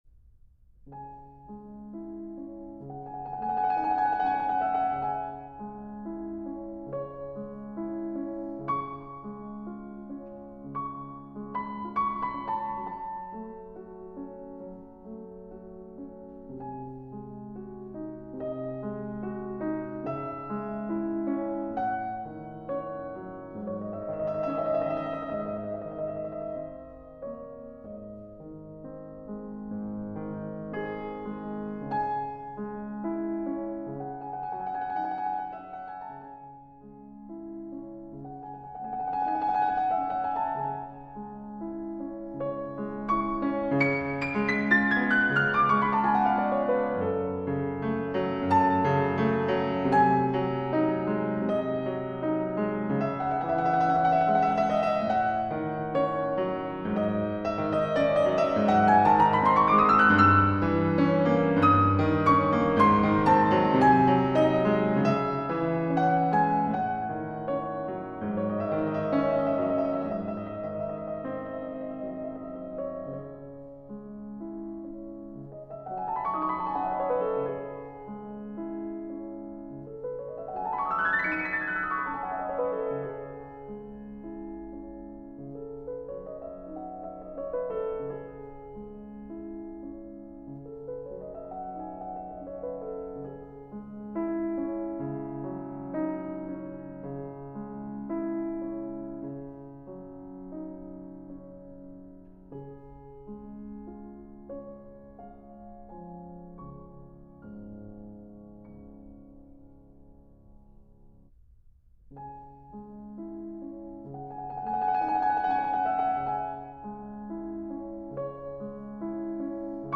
И вдруг – звук фортепиано.
Ноктюрн до-диез минор Шопена. Музыка, полная тоски, боли, надежды, – всего того, что она чувствовала в тот момент.